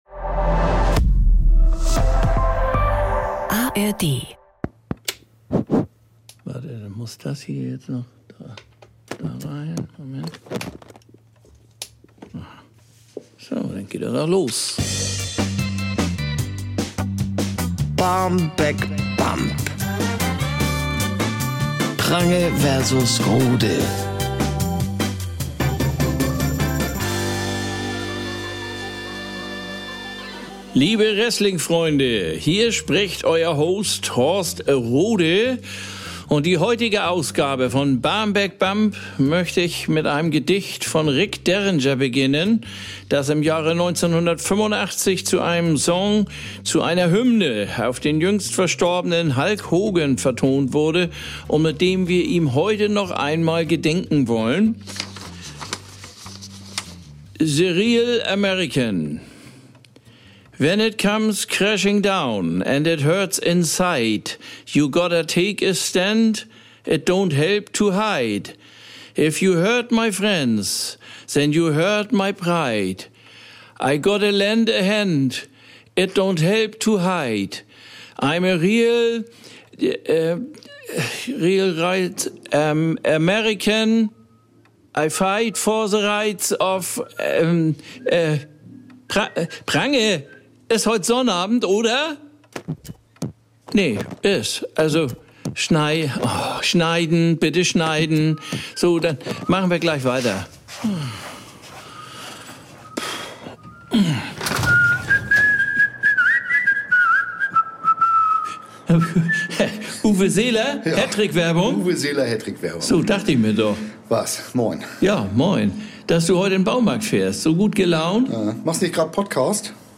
Sprecherin: Doris Kunstmann